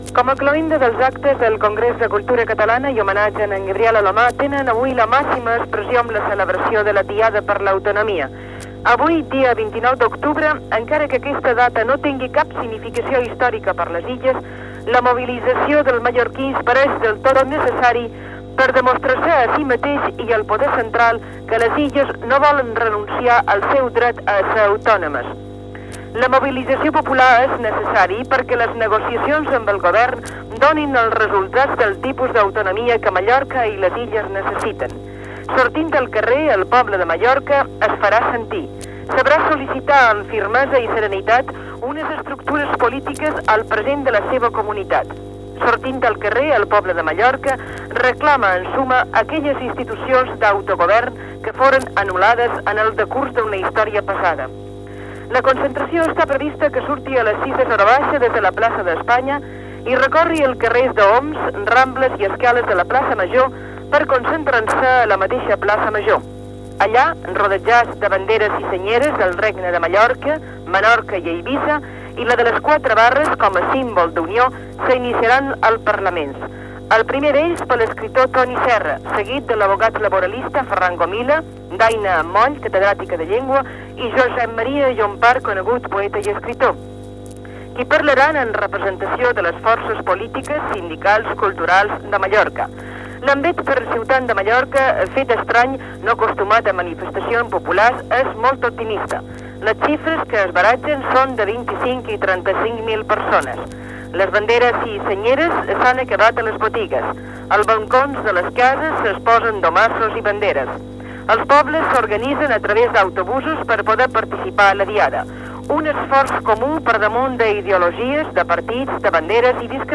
Crònica, des de Radio Popular de Mallorca, de la Diada per a l'Autonomia on es reclamarà l'autogovern i l'Estatut d'Autonomia per a les Illes Balears
Informatiu